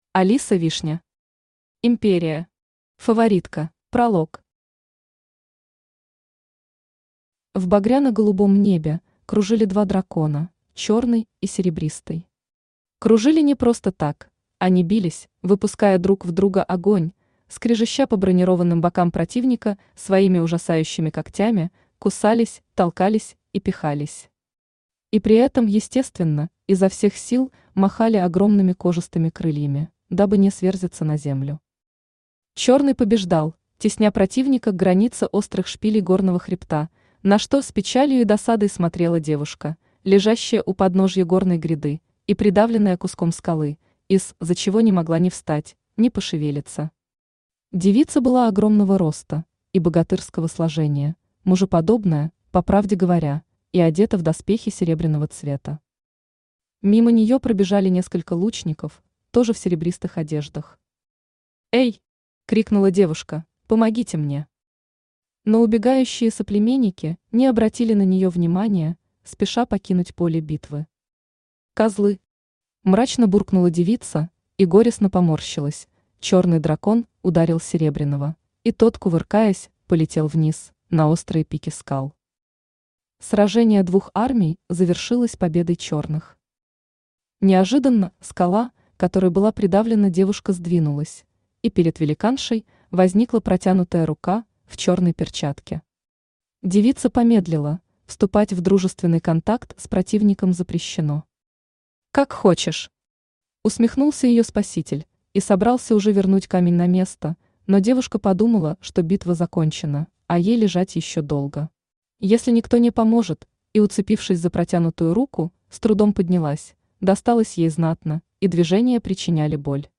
Aудиокнига Империя. Фаворитка Автор Алиса Вишня Читает аудиокнигу Авточтец ЛитРес.